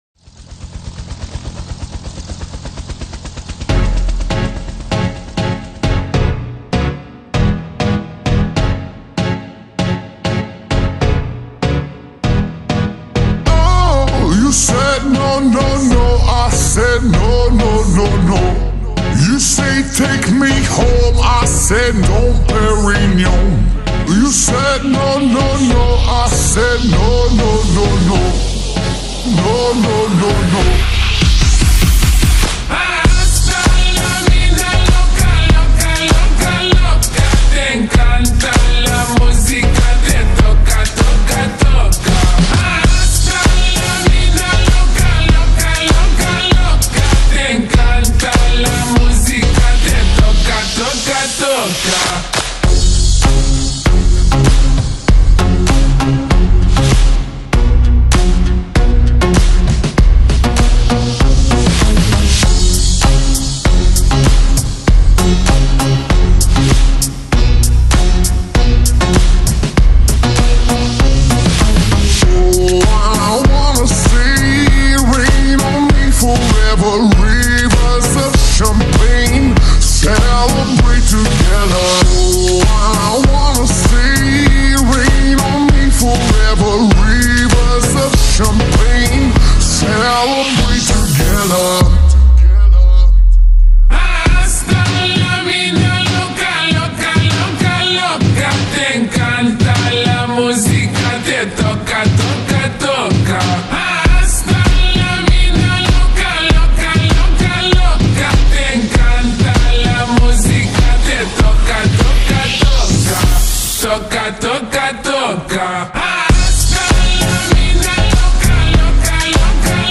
با ریتمی آهسته شده
شاد